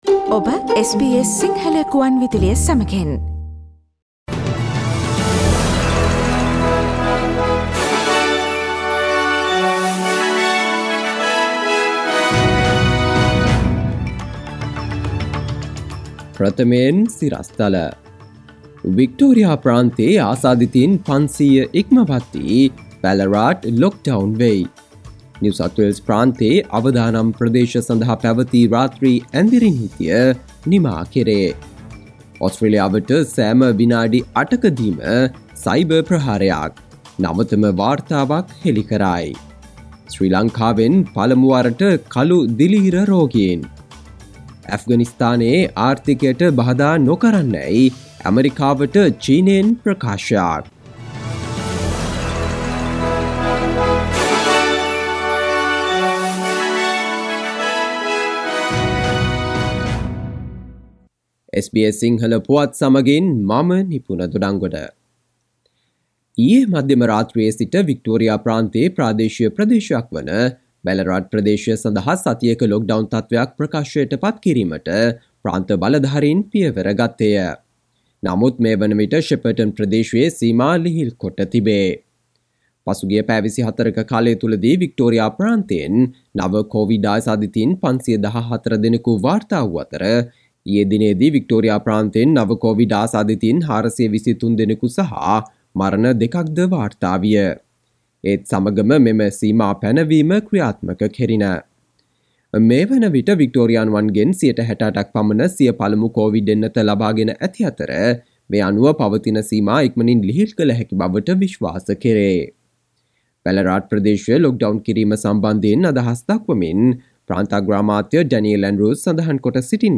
සවන්දෙන්න 2021 සැප්තැම්බර්16 වන බ්‍රහස්පතින්දා SBS සිංහල ගුවන්විදුලියේ ප්‍රවෘත්ති ප්‍රකාශයට...